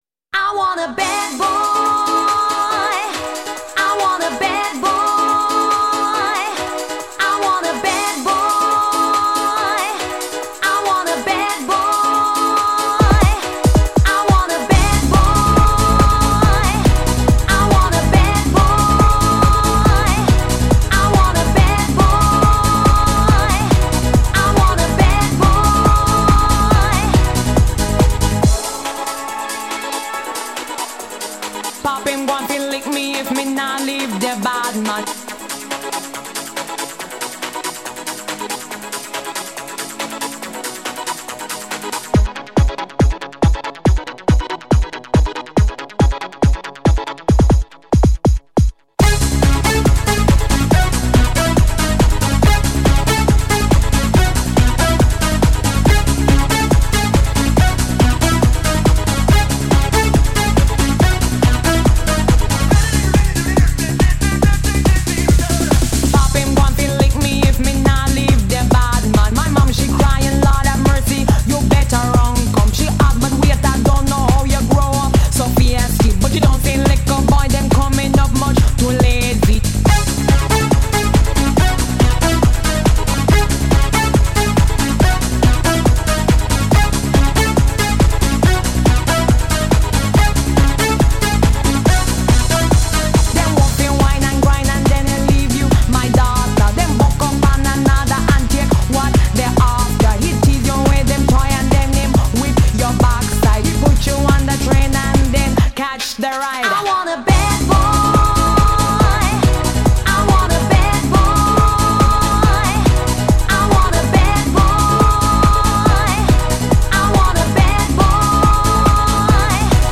Жанр: Euro-House